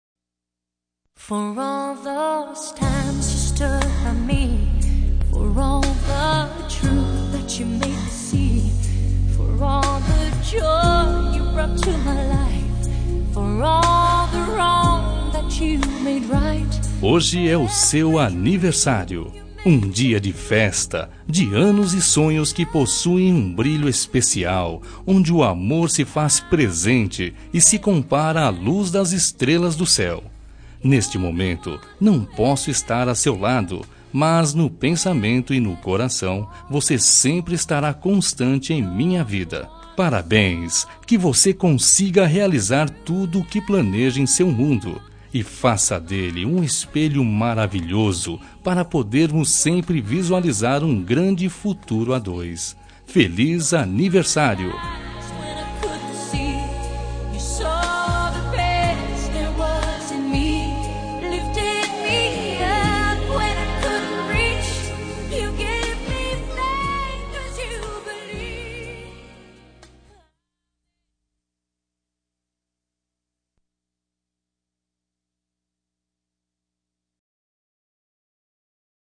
Telemensagem Aniversário de Paquera -Voz Masculina – Cód: 1265 Distante